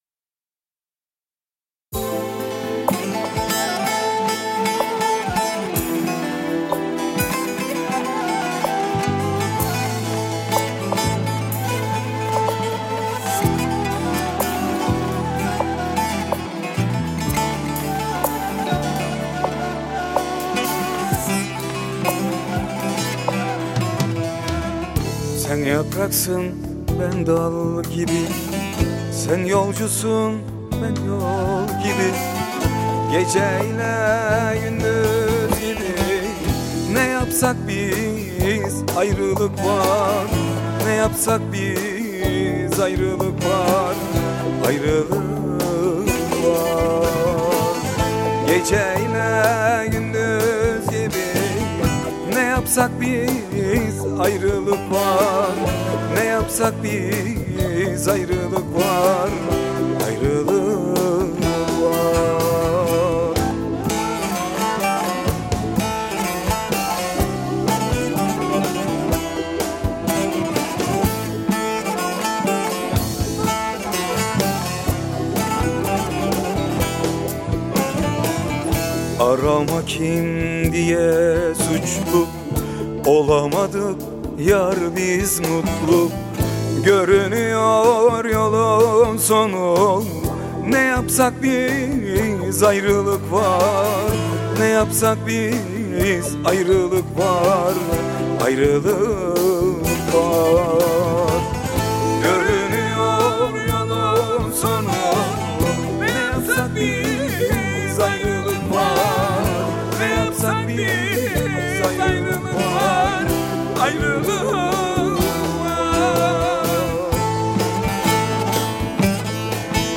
Pop Fantazi